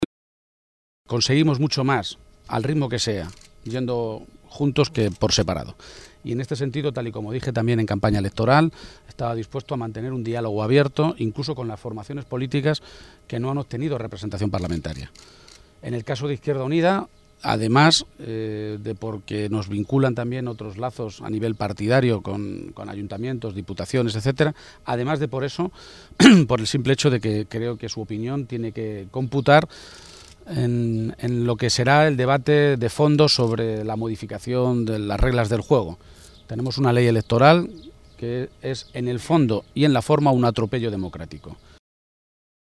García-Page se pronunciaba de esta manera esta mañana, en Toledo, en una comparecencia ante los medios de comunicación minutos antes de que comenzara esa conversación con responsables de IU a nivel regional, en las Cortes de Castilla-La Mancha.